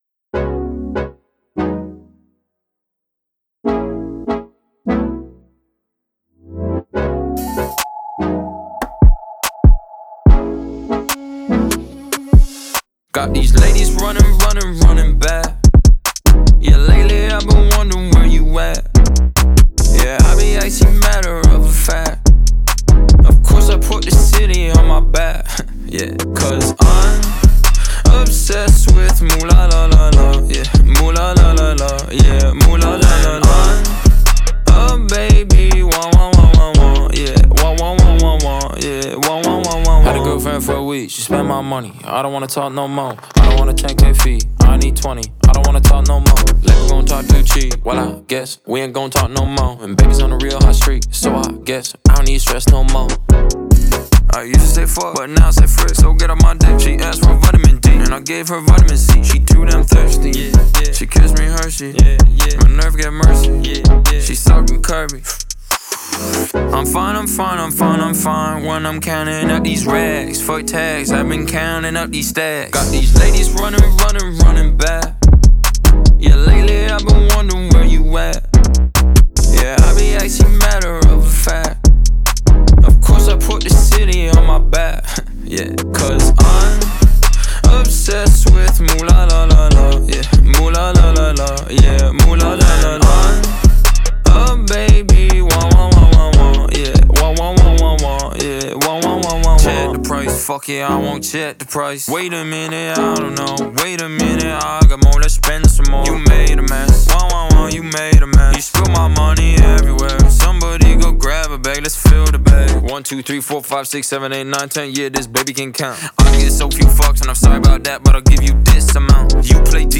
это энергичная и зажигательная композиция в жанре хип-хоп